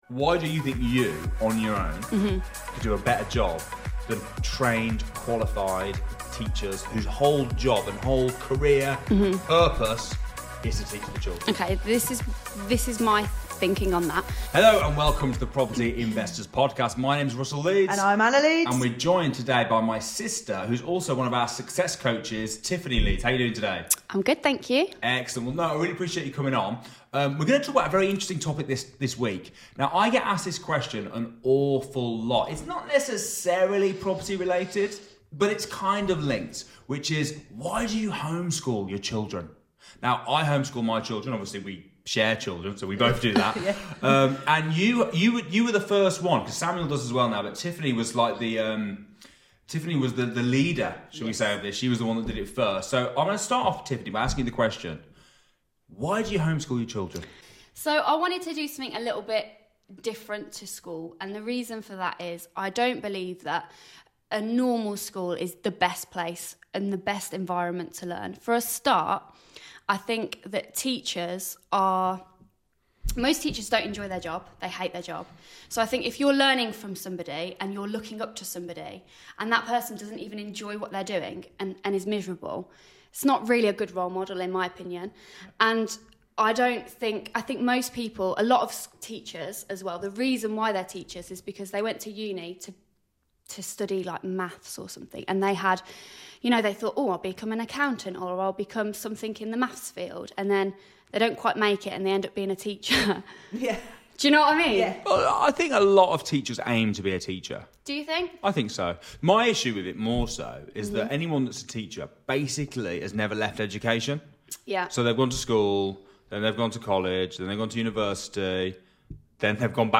Tune in for a candid conversation about education, parenting, and the power of property investment to enable a flexible lifestyle.